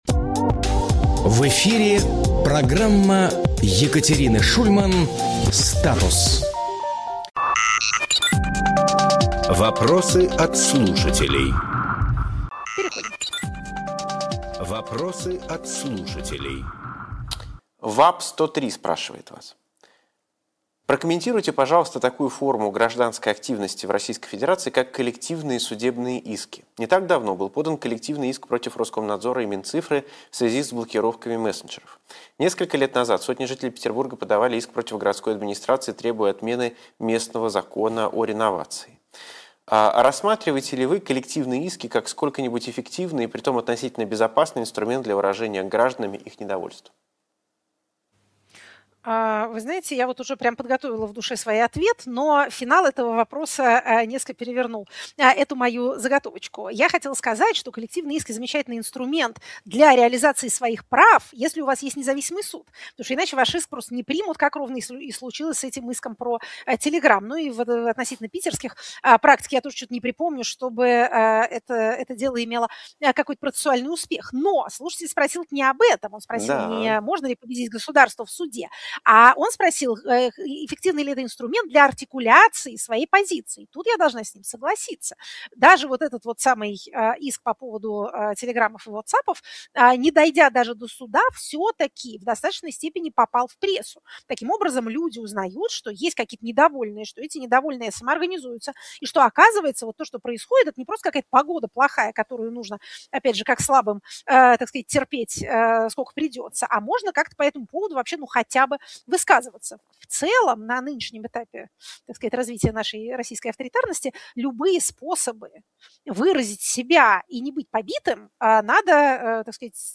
Екатерина Шульманполитолог
Фрагмент эфира от 20.01.2026